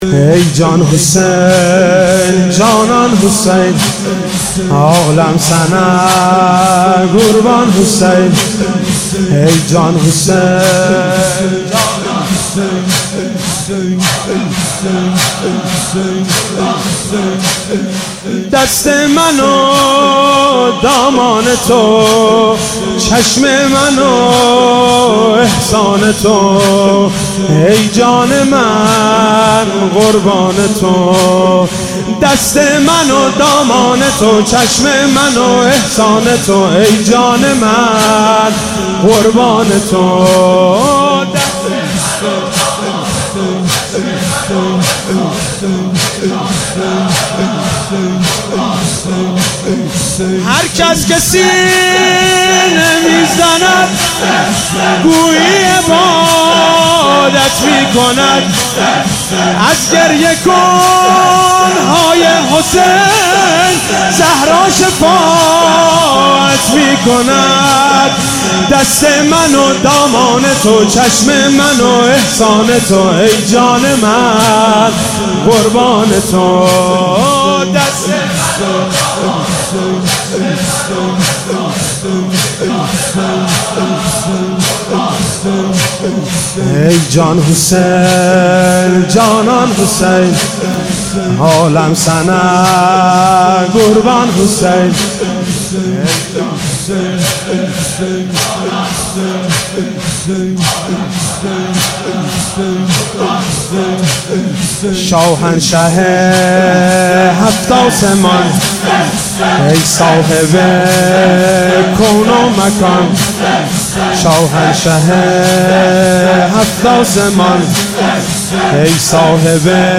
شب دوم محرم الحرام 95
روضه